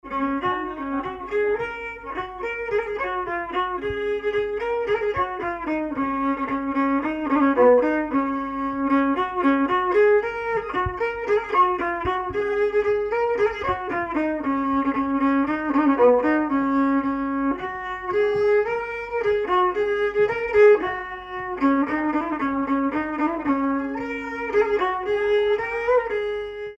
danse : marche
circonstance : bal, dancerie
Pièce musicale inédite